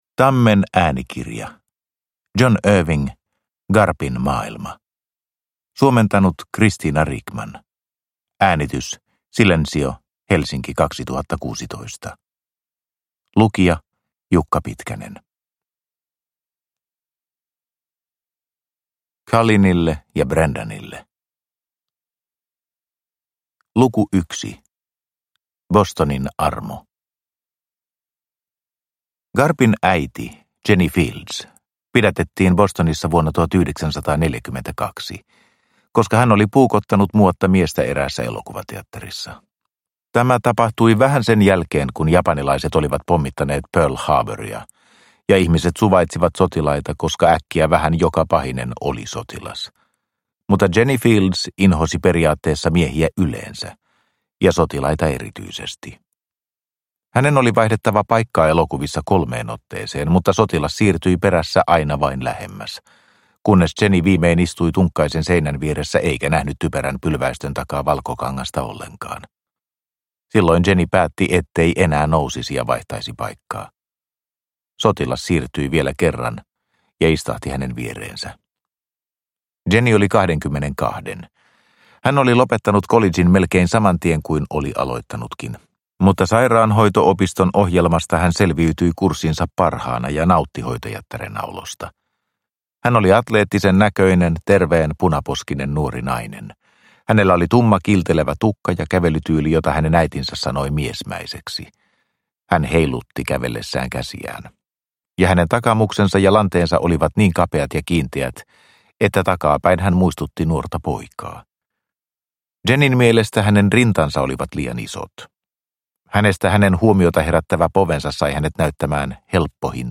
Garpin maailma – Ljudbok – Laddas ner